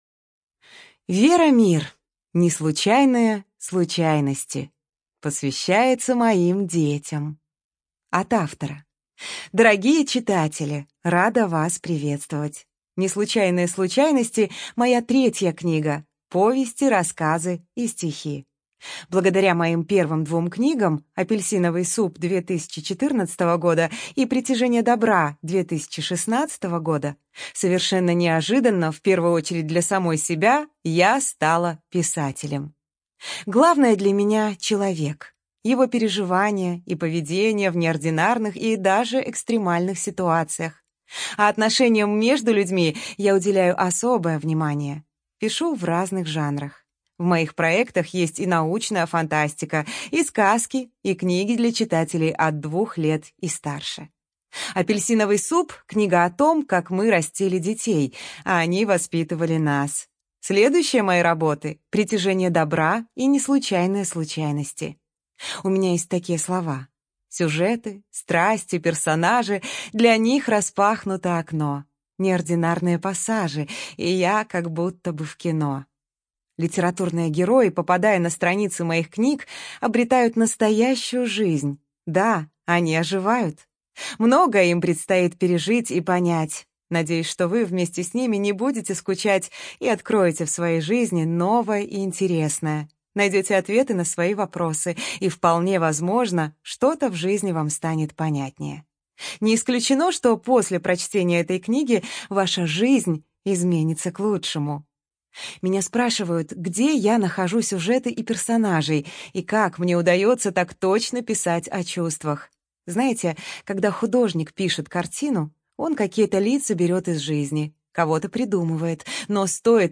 ЖанрСовременная проза, Поэзия